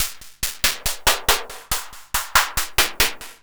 tx_perc_140_splurdge2.wav